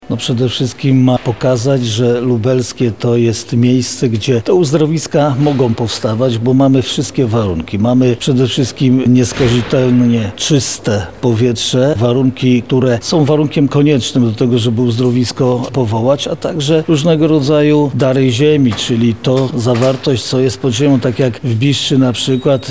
– mówi Jarosław Stawiarski, Marszałek Województwa Lubelskiego.
konferencja-uzdrowiska-marszalek.mp3